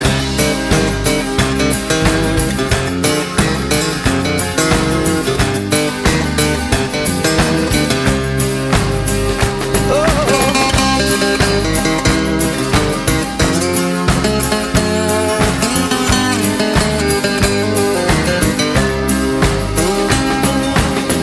• Качество: 197, Stereo
гитара
без слов
инструментальные
ретро
Гитарный проигрыш